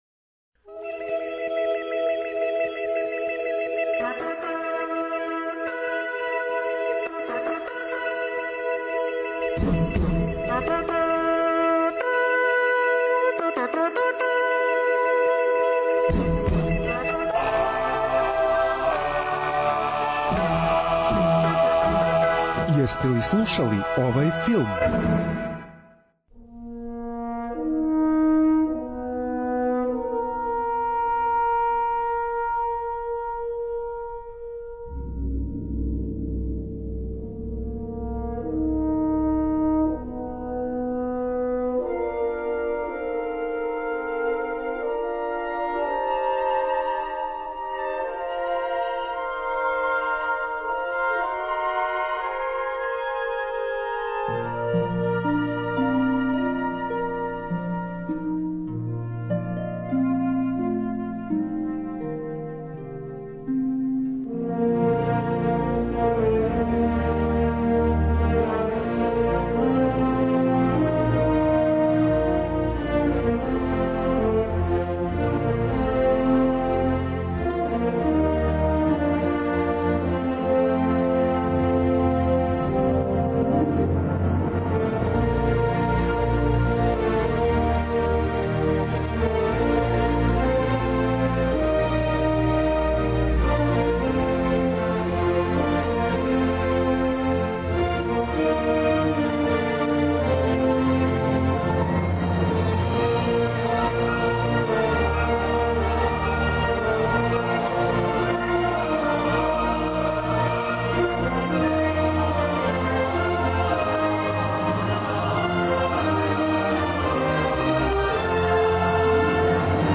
Филмска музика и филмске вести.